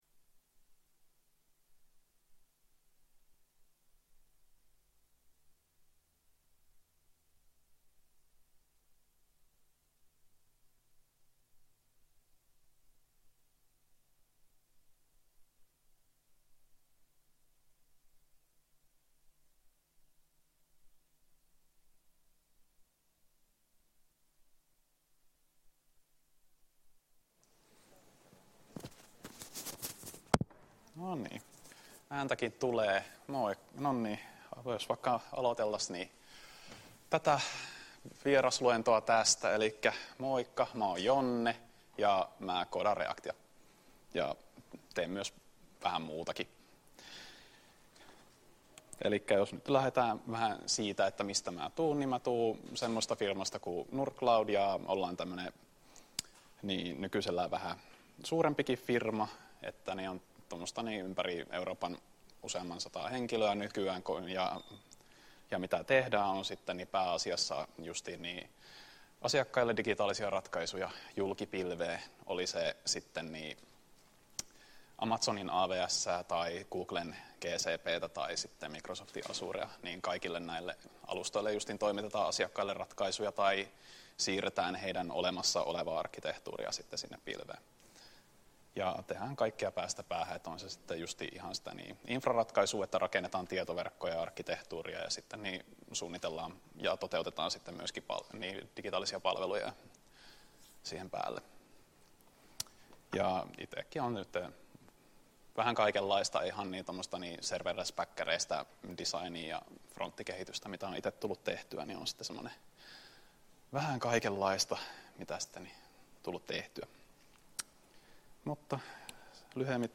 Luento 30.11.2018